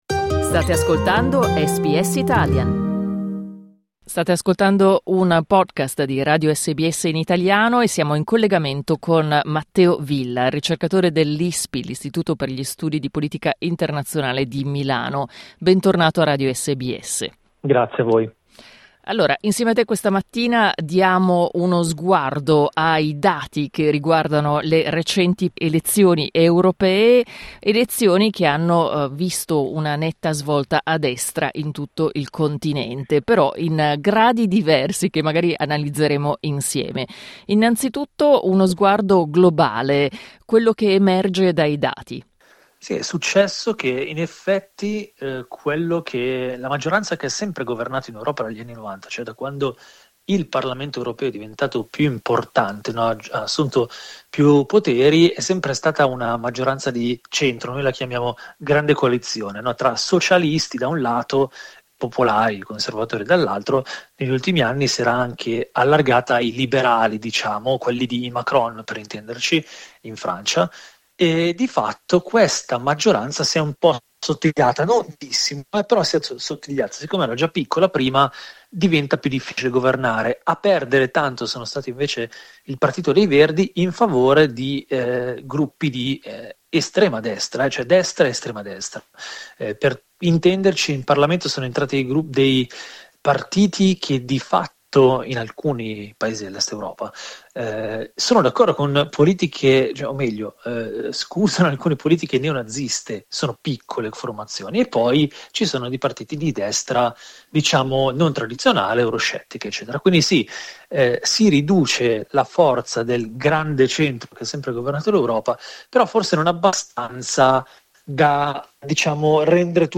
Clicca sul tasto 'play' in alto per ascoltare l'intervista integrale In Italia si è votato per i 76 membri del Parlamento europeo spettanti all'Italia, e il partito Fratelli d’Italia si è confermato lo schieramento con più consensi, seguito dal Partito Democratico.